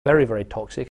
I do hear Cameron’s LOT vowel as very far back.
At least some of the time, Cameron also seems to have apical (tongue-tip) [t] and [d]. An apical [t] combines with that far-back LOT vowel to give a pronunciation of toxic that strikes me as very Cameronian: